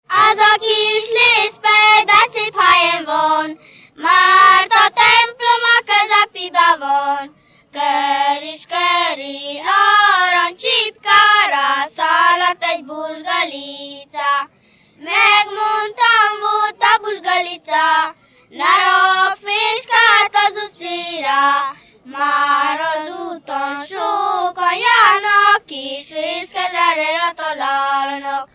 Moldva és Bukovina - Moldva - Lészped
ének
Stílus: 8. Újszerű kisambitusú dallamok